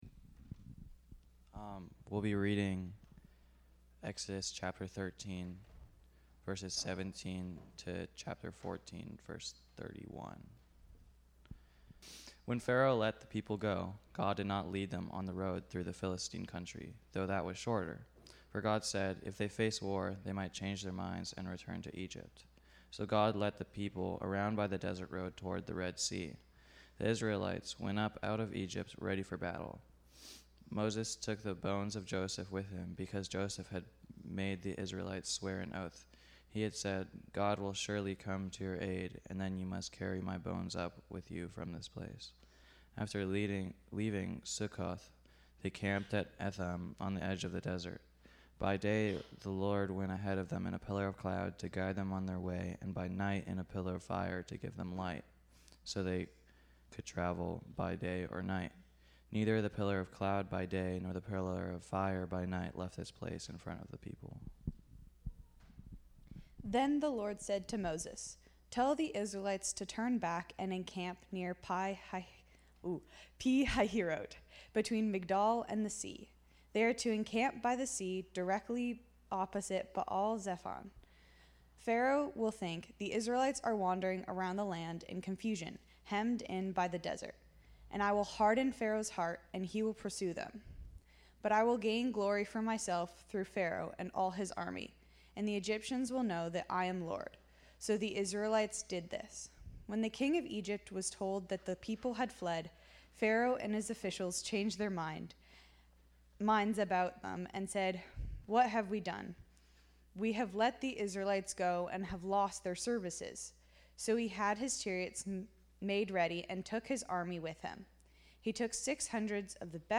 Chapter 14 From this series Current Sermon January 4 2026